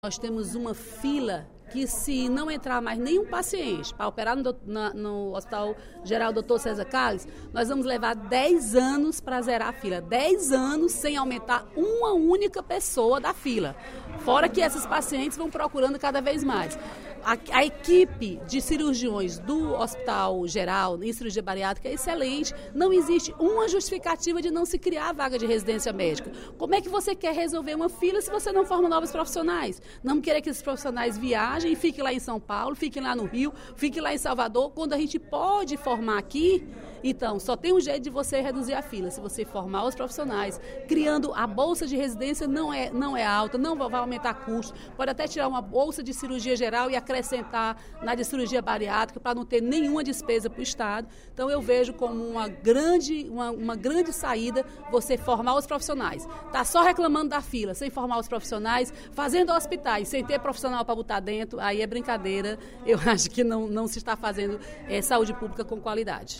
A deputada Dra. Silvana (PMDB) pediu, em pronunciamento no primeiro expediente da sessão plenária da Assembleia Legislativa desta quarta-feira (30/09), apoio ao projeto de indicação, de sua autoria, que pretende criar a residência médica para cirurgias bariátricas, realizadas em pacientes com obesidade mórbida.